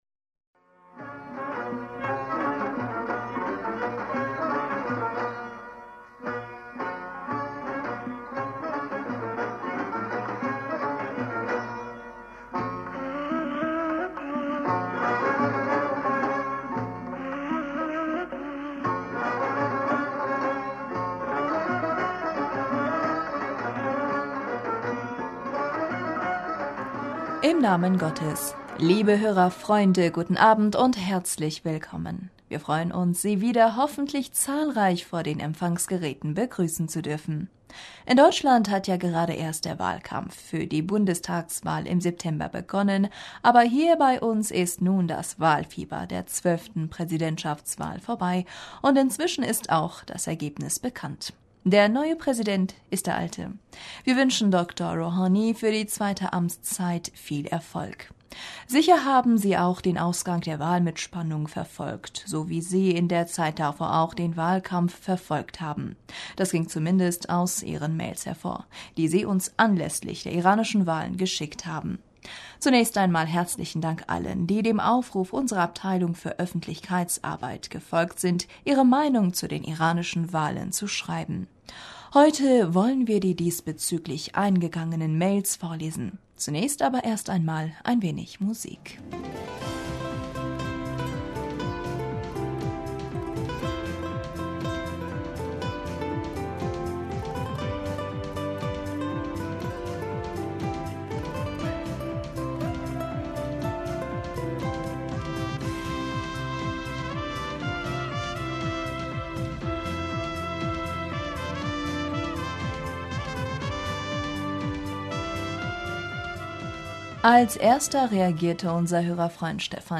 Hörerpostsendung am 21. Mai 2017
Heute wollen wir die diesbezüglich eingegangenen Mails vorlesen. Zunächst aber erst einmal ein wenig Musik.